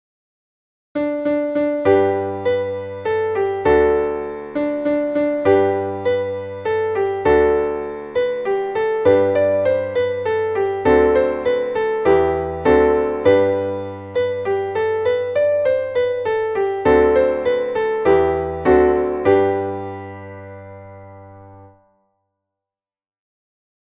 Traditionelles Volkslied